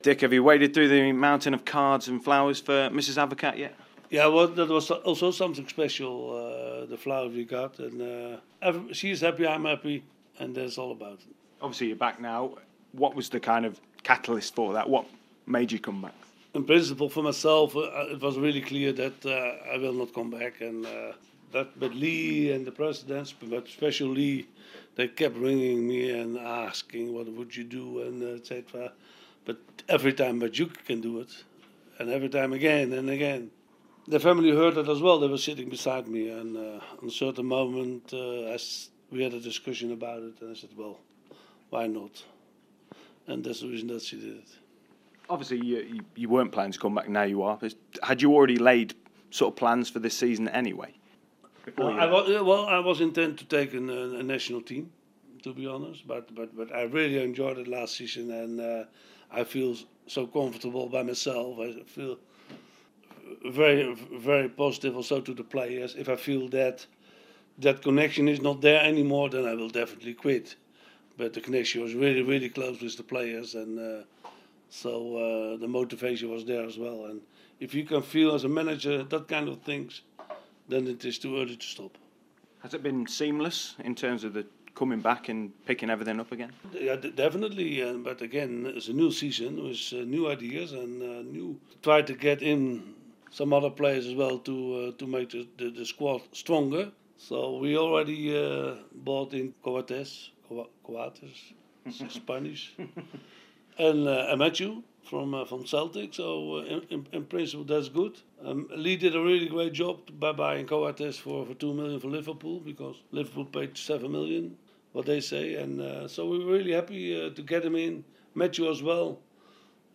Dick Advocaat's first interview of the season